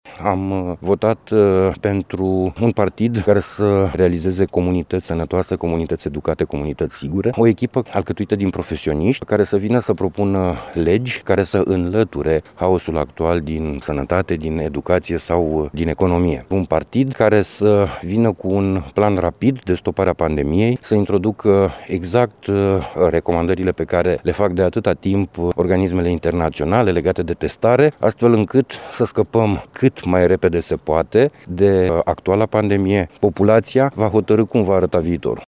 Florin Buicu spune că a votat pentru profesioniștii care să stopeze pandemia și îi invită pe oameni să voteze astăzi pentru a decide cum va arăta viitorul: